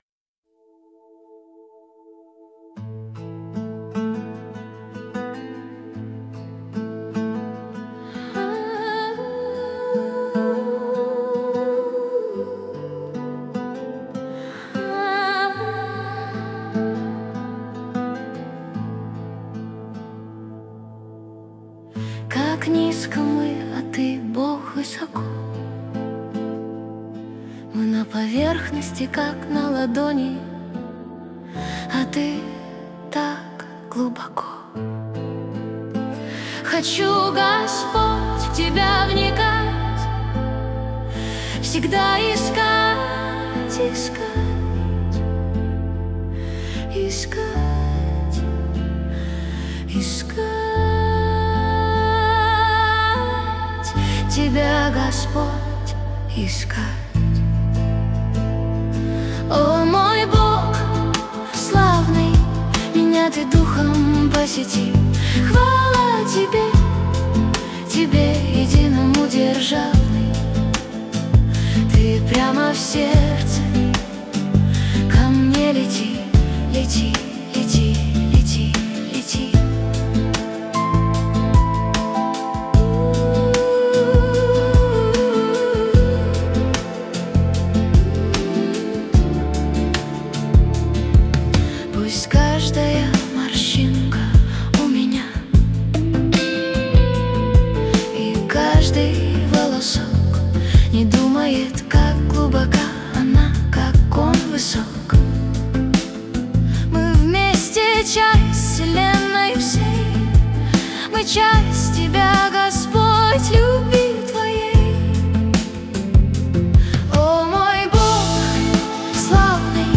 песня ai
111 просмотров 454 прослушивания 55 скачиваний BPM: 76